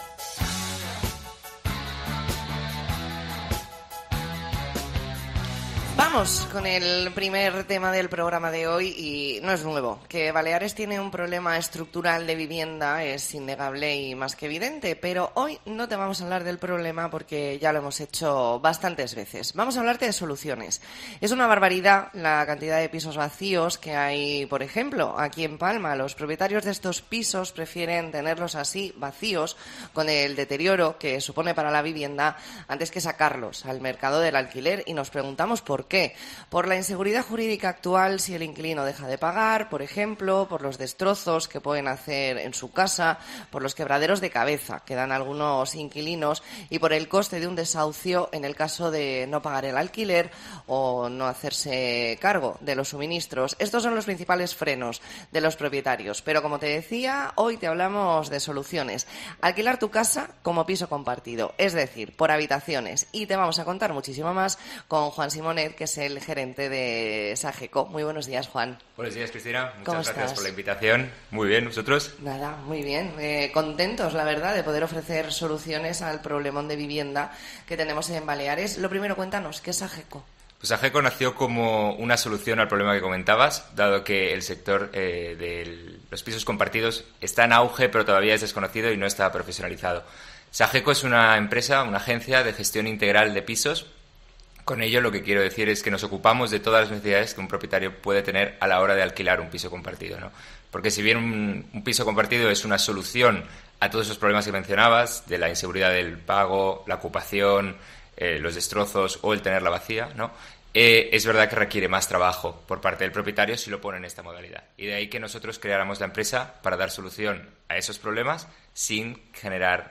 Entrevista en La Mañana en COPE Más Mallorca, miércoles 18 de octubre de 2023.